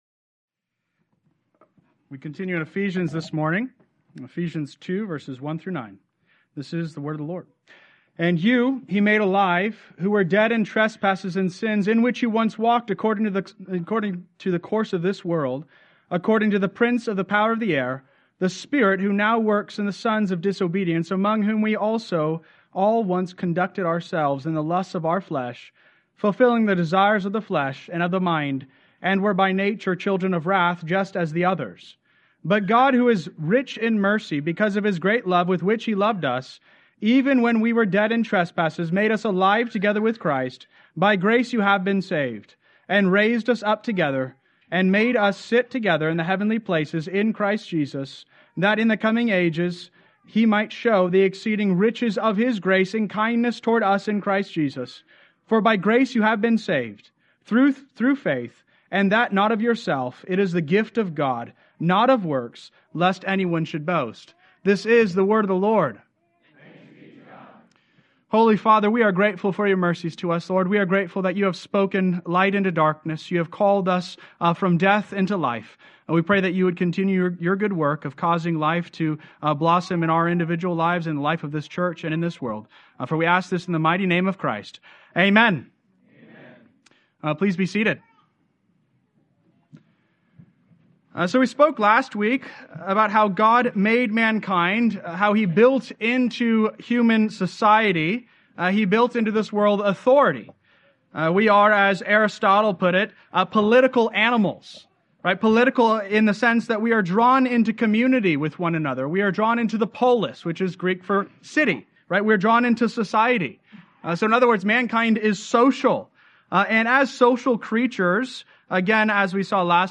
Sermon Outline: 2025-03-09 – Outline Ephesians 2a (Biography)